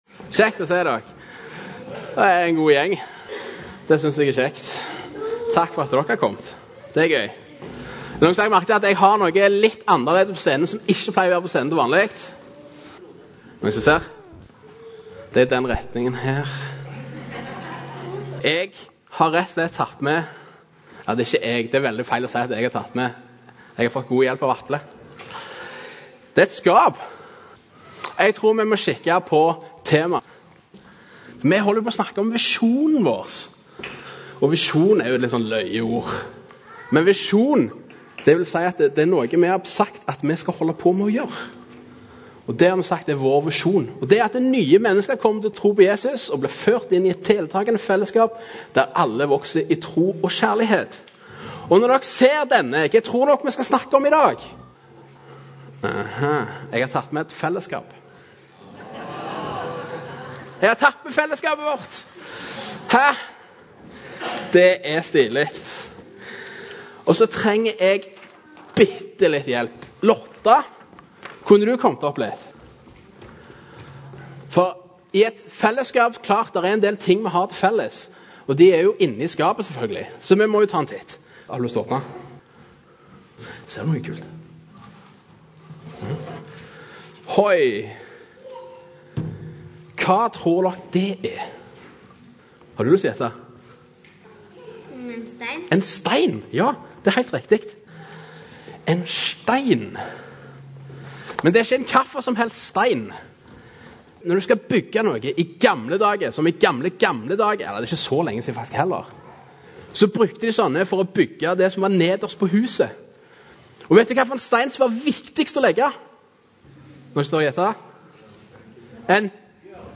Taler fra "ByMenigheten - Sandnes" (Lundehaugen menighet) i Sandnes, Norge.